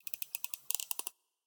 Minecraft Version Minecraft Version 1.21.5 Latest Release | Latest Snapshot 1.21.5 / assets / minecraft / sounds / mob / dolphin / idle_water6.ogg Compare With Compare With Latest Release | Latest Snapshot
idle_water6.ogg